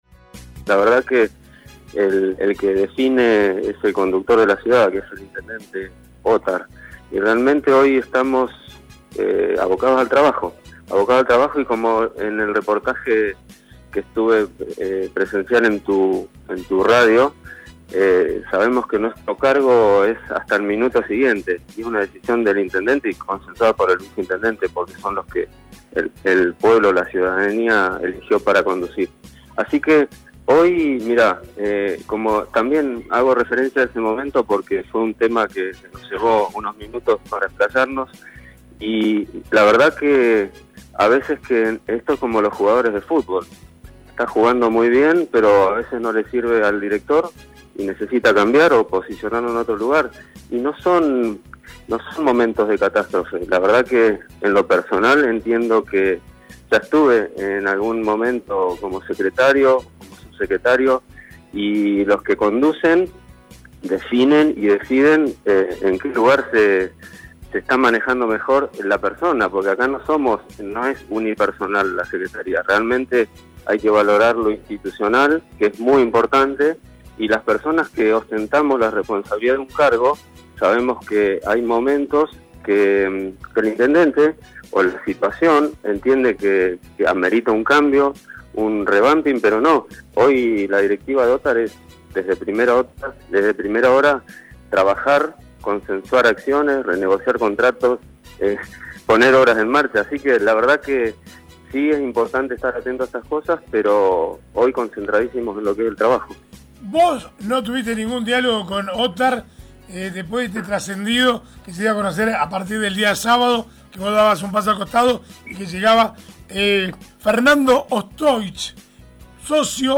El secretario de Obra Pública de Comodoro, Luis Romero, confirmó en el aire de RADIOVISIÓN que el Intendente lo ratificó en su cargo: